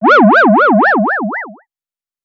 ihob/Assets/Extensions/CartoonGamesSoundEffects/Dizzy_v1/Dizzy_v3_wav.wav at master